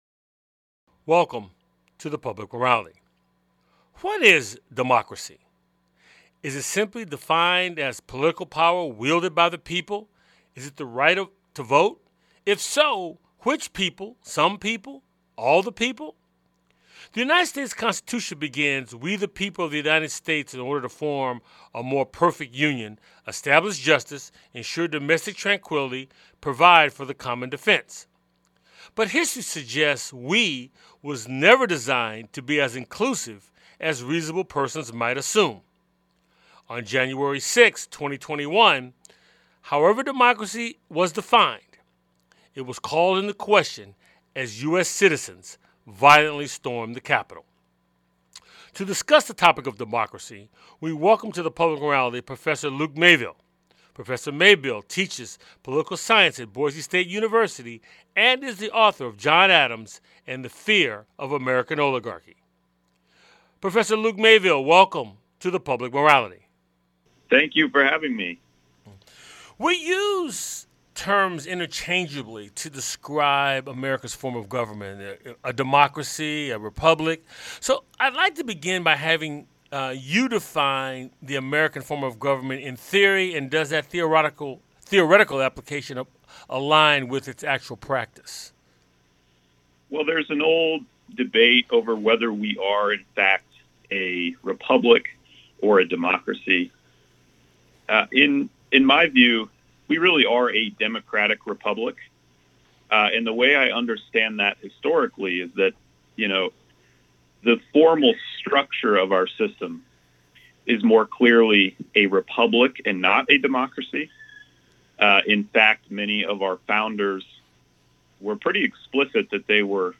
The show airs on 90.5FM WSNC and through our Website streaming Tuesdays at 7:00p.